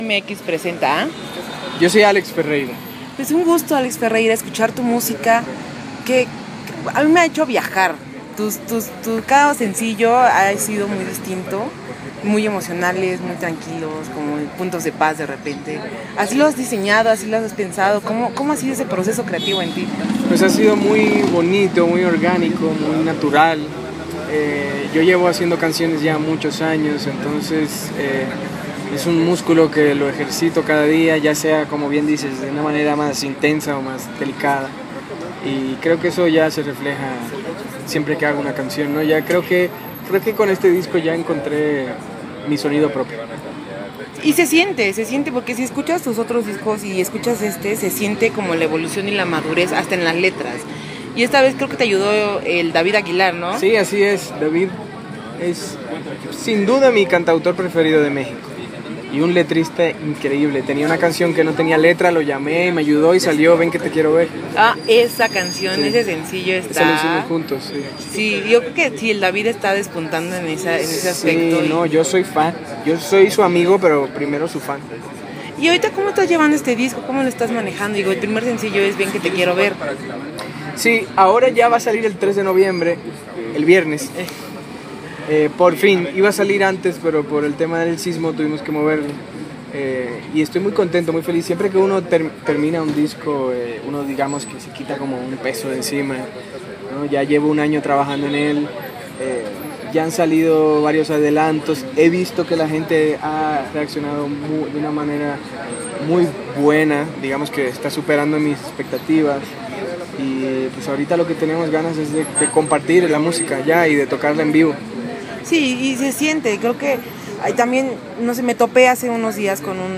Tuvimos la oportunidad de hablar con Alex Ferreira antes de un par de conciertos en la Ciudad de México y en donde nos contó este nuevo sonido con el que está experimentando, como fue abandonar una gran disquera en España para volverse independiente, la creatividad que está teniendo en estos momentos, los covers que ha realizado y su amor a los Beach Boys, entre más cosas.